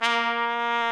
Index of /90_sSampleCDs/Roland L-CD702/VOL-2/BRS_Tpt Cheese/BRS_Cheese Tpt